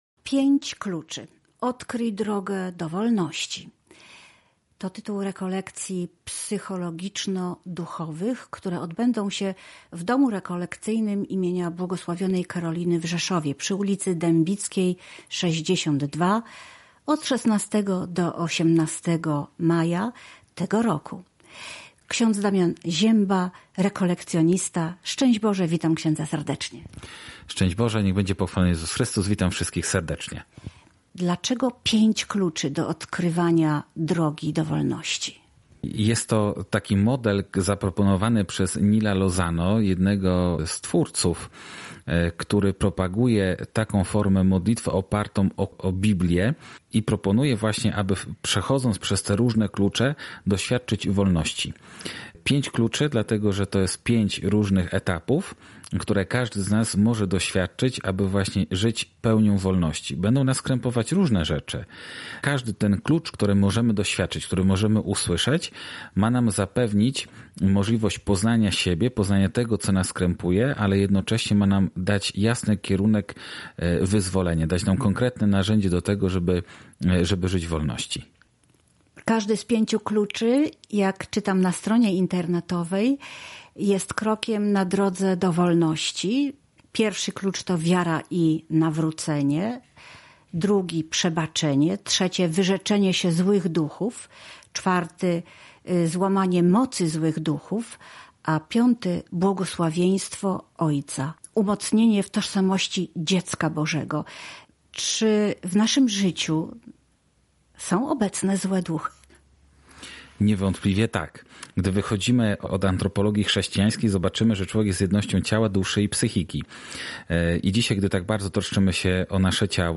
Rekolekcje i warsztaty psychologiczno-duchowe w Rzeszowie • W ogrodzie wiary • Polskie Radio Rzeszów